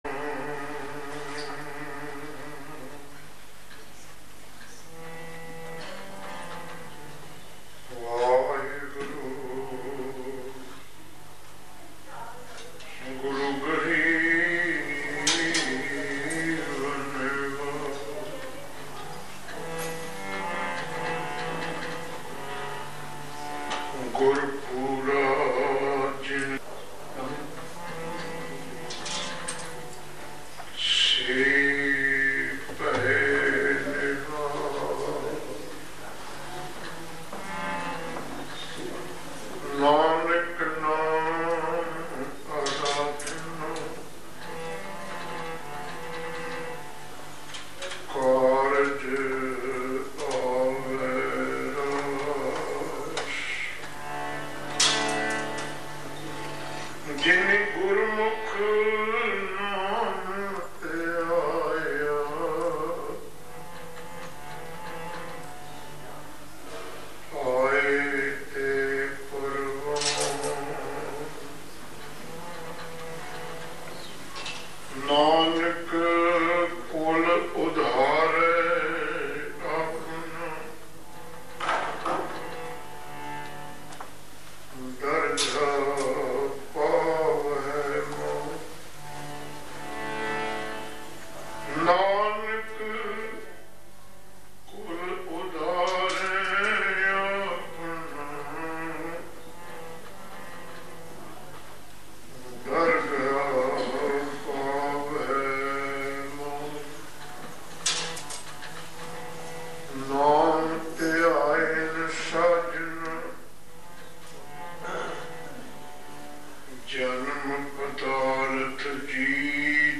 Album:Amrit Vela Sach Nao Genre: Gurmat Vichar